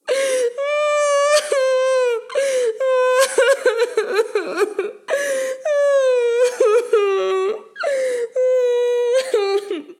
Llanto de mujer largo 2
llanto
mujer
Sonidos: Acciones humanas
Sonidos: Voz humana